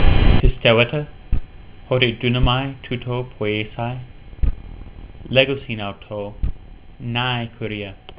You can click on the verse to hear me read it.